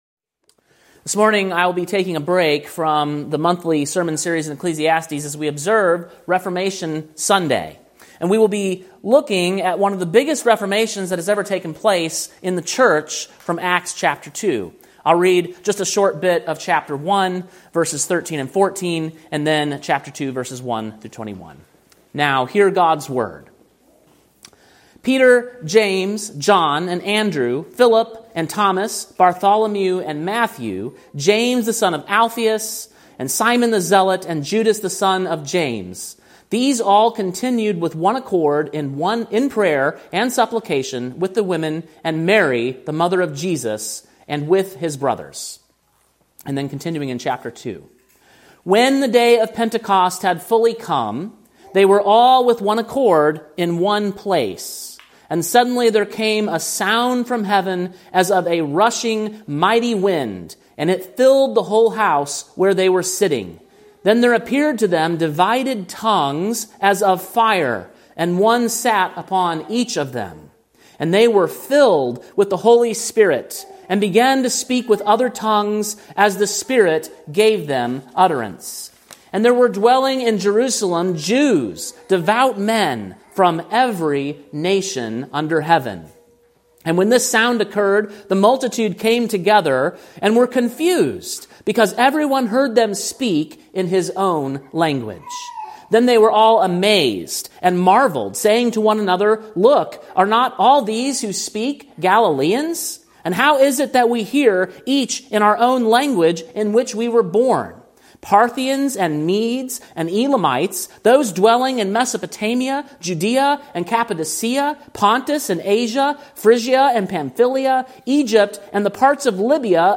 Sermon preached on October 26, 2025, at King’s Cross Reformed, Columbia, TN.